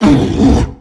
c_abrute_hit2.wav